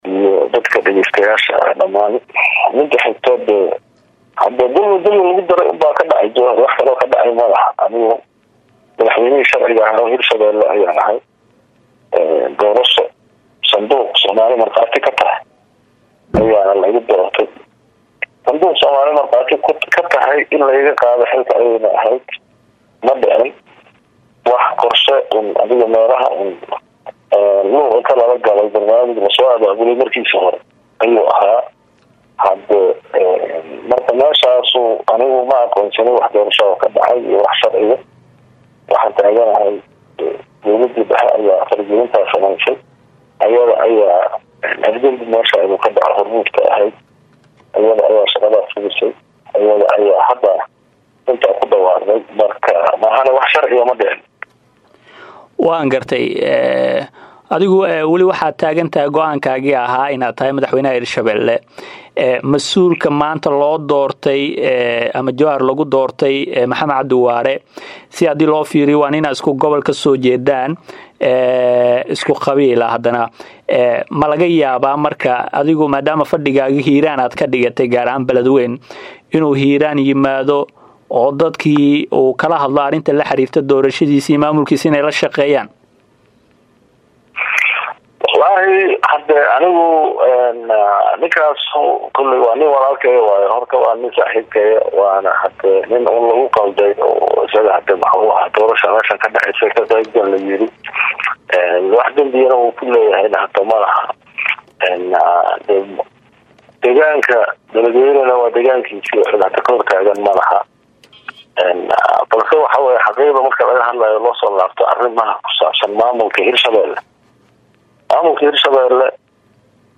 Isaga oo ku sugan dalka Kenya ayuu Caawa Wareysi dhinacyo badan taabanaya siiyay Radio Dalsan xarunta Muqdisho waxa uuna sheegay in uu gebi ahaanba qaadacay doorashadaas.